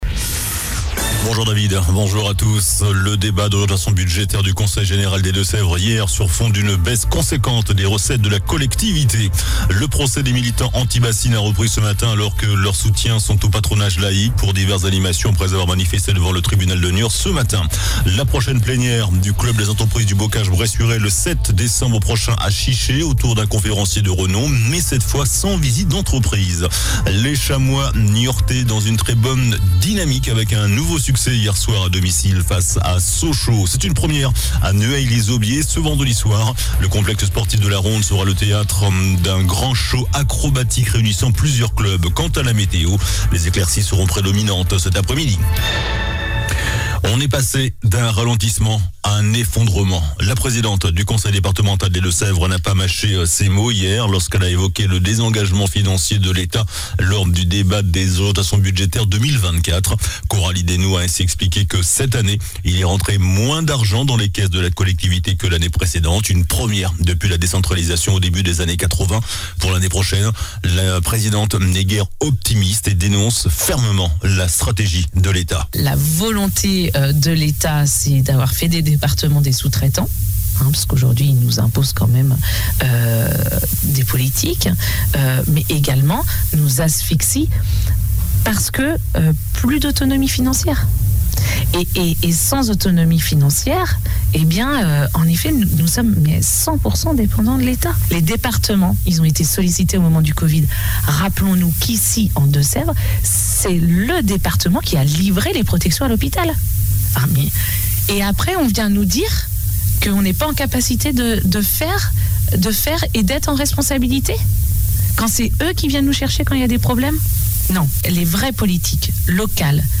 JOURNAL DU MARDI 28 NOVEMBRE ( MIDI )